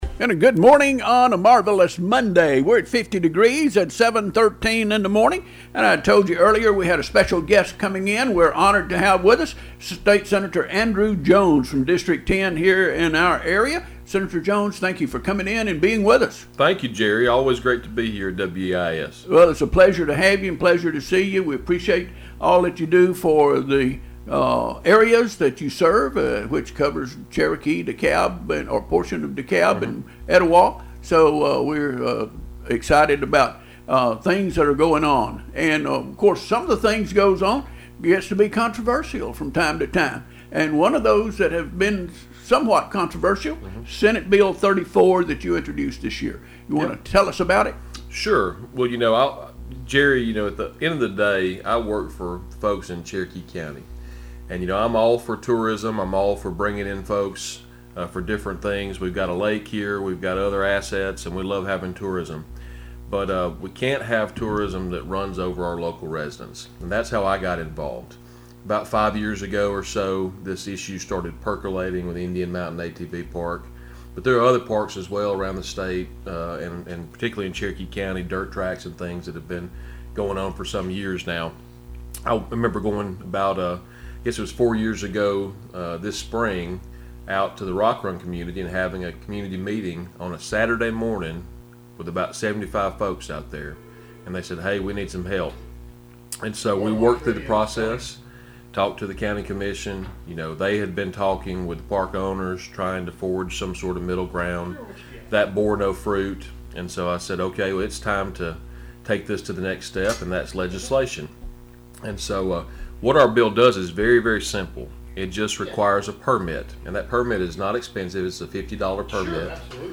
Senator-Andrew-Jones-Interview-On-air-41425.mp3